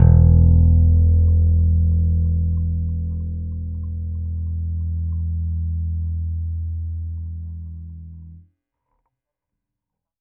Trem Trance Bass Ending.wav